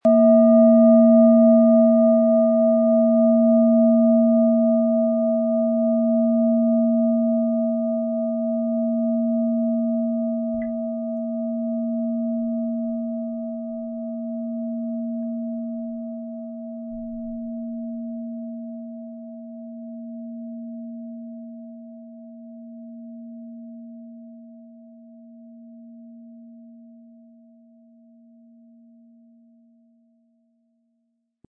Planetenton
Wie klingt diese Planetenton-Klangschale Mond?
Um den Original-Klang genau dieser Schale zu hören, lassen Sie bitte den hinterlegten Sound abspielen.
Lieferung inklusive passendem Klöppel, der gut zur Planetenschale passt und diese sehr schön und wohlklingend ertönen lässt.
HerstellungIn Handarbeit getrieben
MaterialBronze